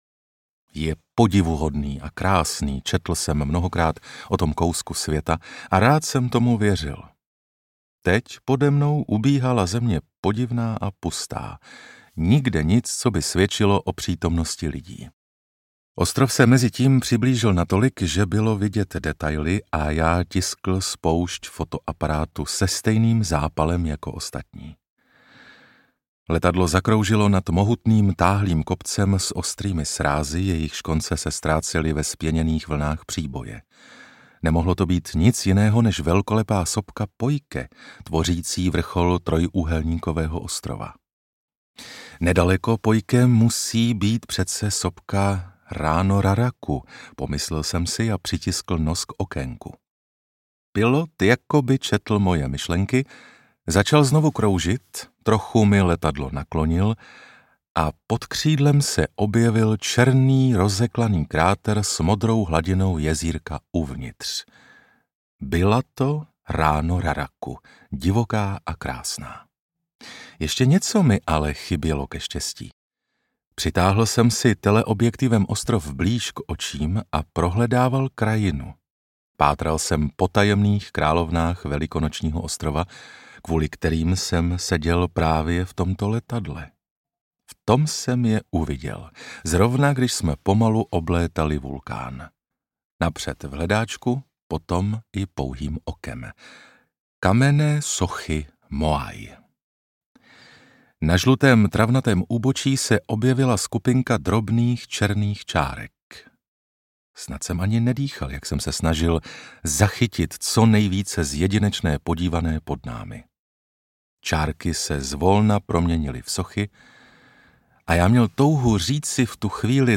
Rapa Nui audiokniha
Ukázka z knihy
Vyrobilo studio Soundguru.